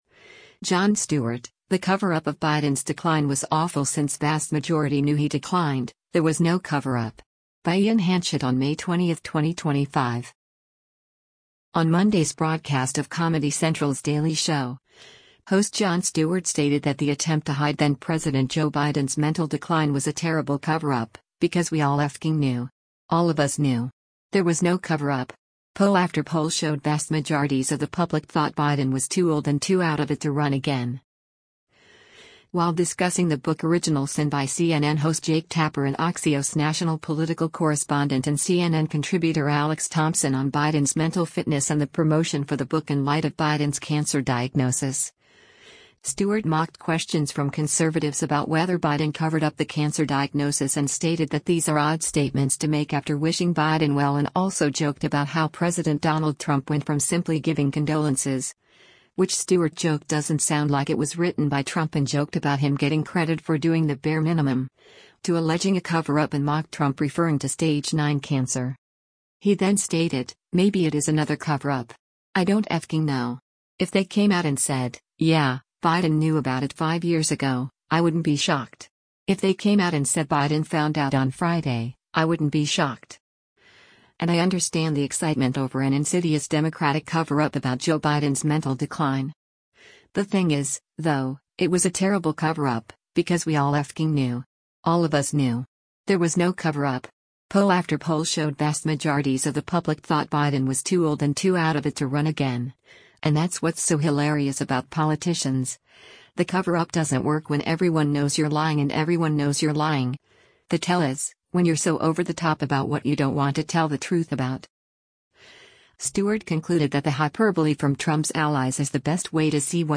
On Monday’s broadcast of Comedy Central’s “Daily Show,” host Jon Stewart stated that the attempt to hide then-President Joe Biden’s mental decline “was a terrible coverup, because we all f*cking knew. All of us knew. There was no coverup. Poll after poll showed vast majorities of the public thought Biden was too old and too out of it to run again.”